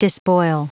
Prononciation du mot despoil en anglais (fichier audio)
Prononciation du mot : despoil